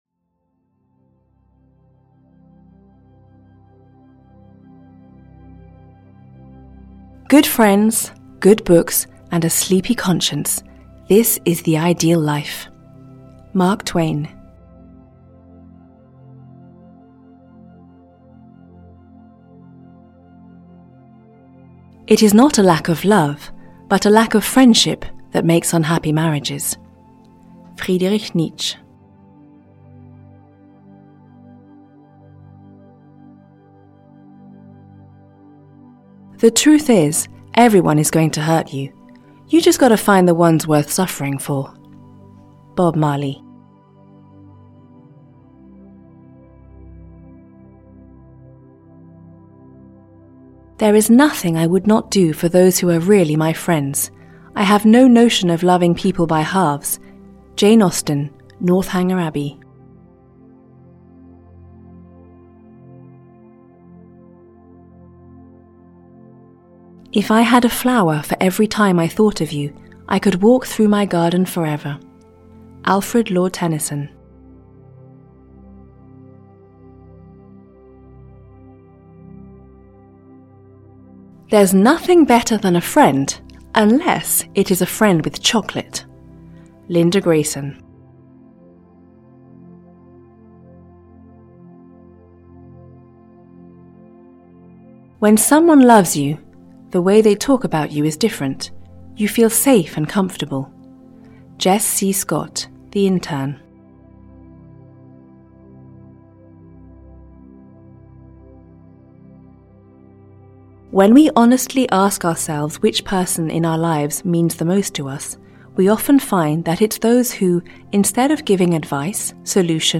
100 Quotes about Friendship (EN) audiokniha
Ukázka z knihy